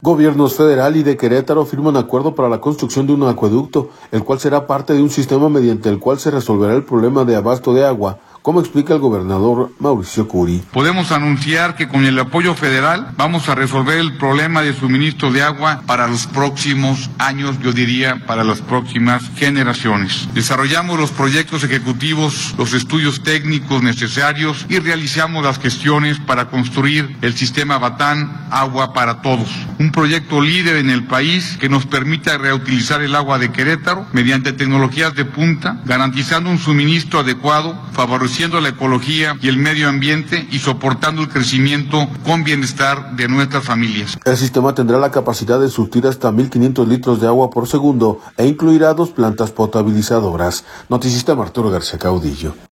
audio Gobiernos federal y de Querétaro firman acuerdo para la construcción de un acueducto, el cual será parte de un sistema mediante el cual se resolverá el problema de abasto de agua, como explica el gobernador Mauricio Kuri.